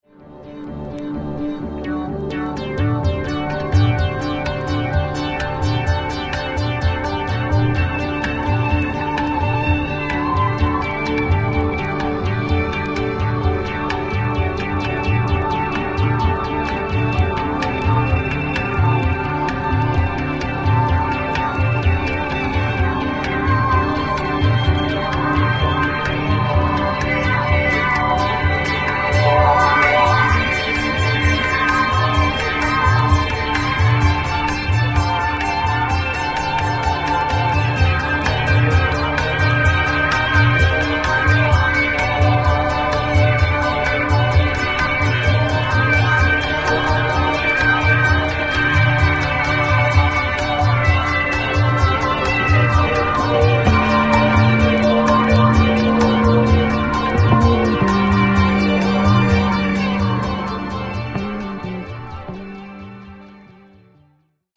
psichedelica e bella nenia…